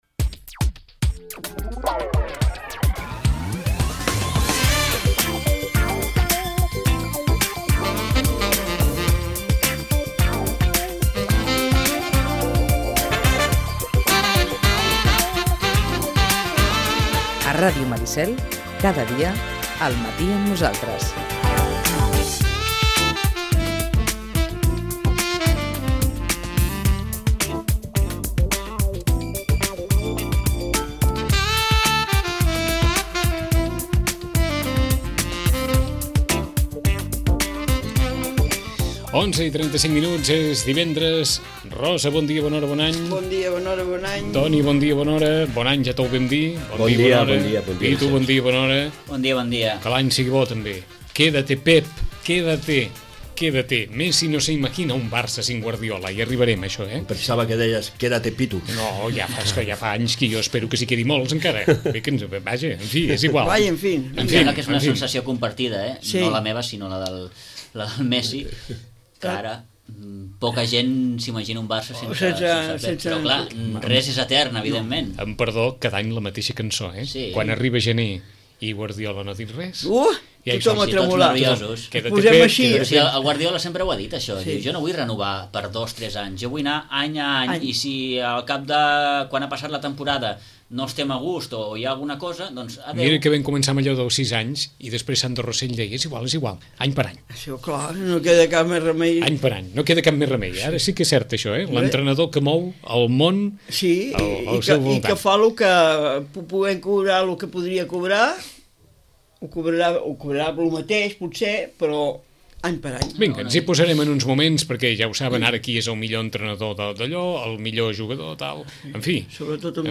Tertúlia esportiva dels divendres